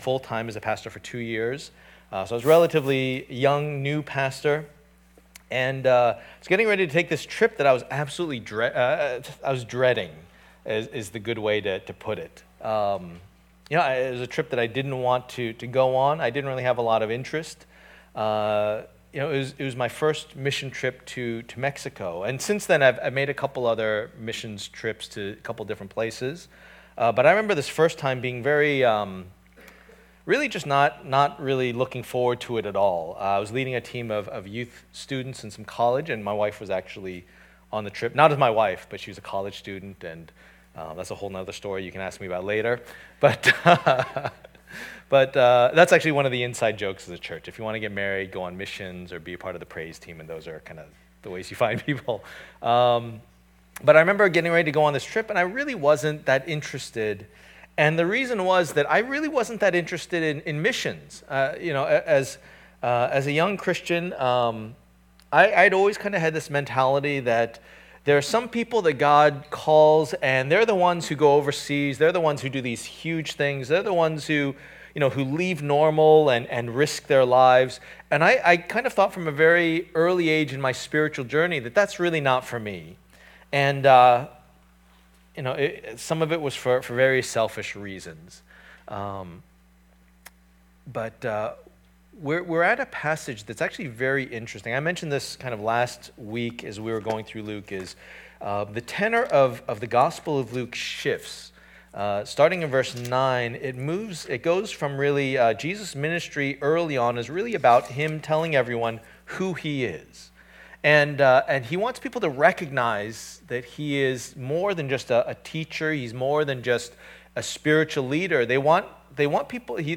The Messiah's Ministry Passage: Luke 10:1-12 Service Type: Lord's Day %todo_render% « Who Do You Say That I Am?